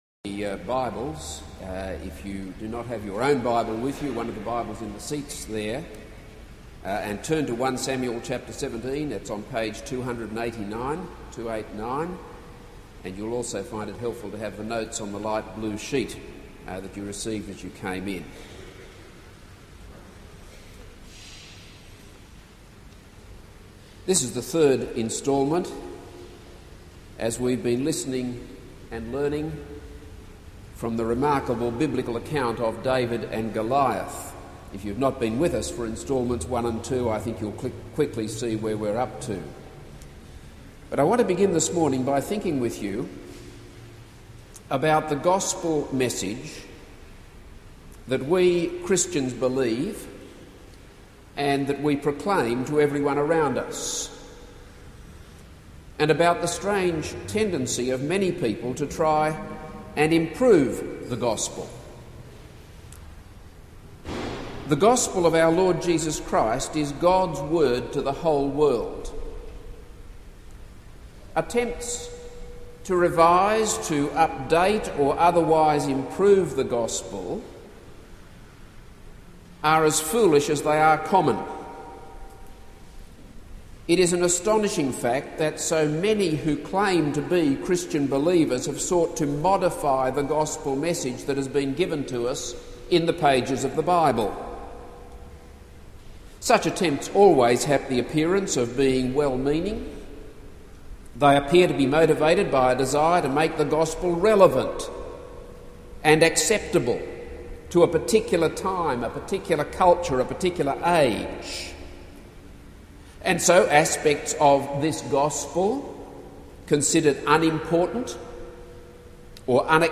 This is a sermon on 1 Samuel 17:31-40.